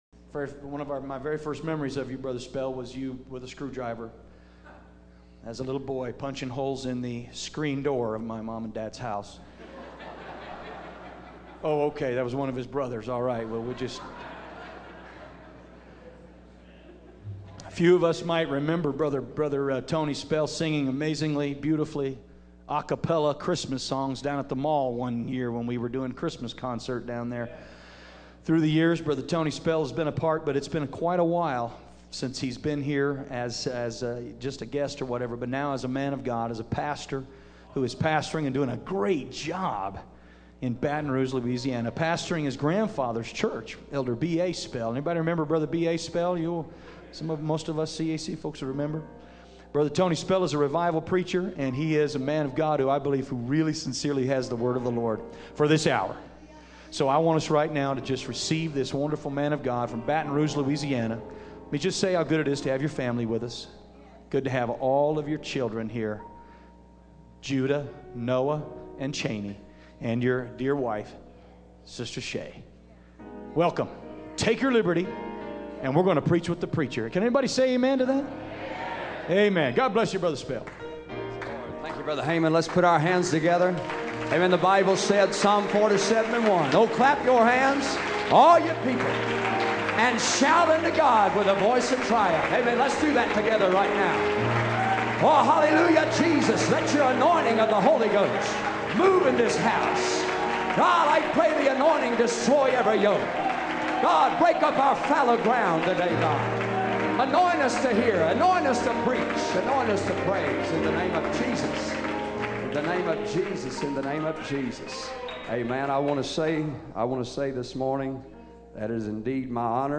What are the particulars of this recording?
The Mile High Conference Revival 2010 I was blessed to attend two wonderful days of The Mile High Conference Revival in Denver Colorado.